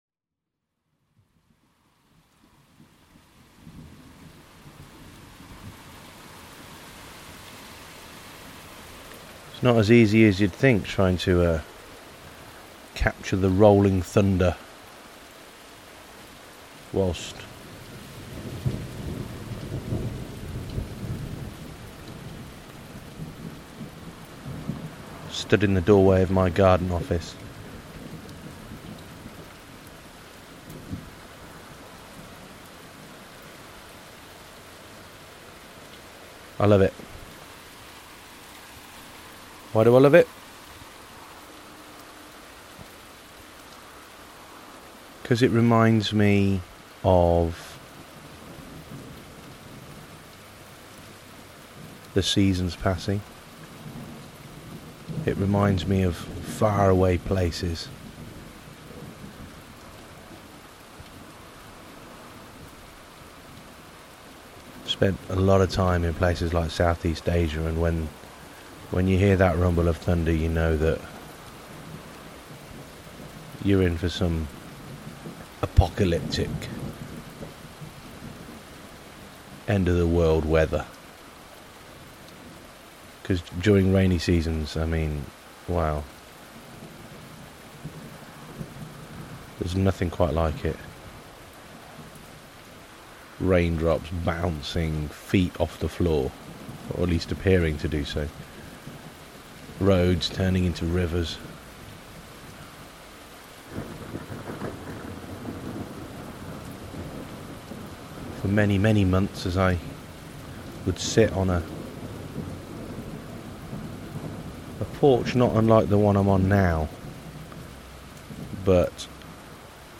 Standing in the garden listening to the rain and i'm reminded of other places and times.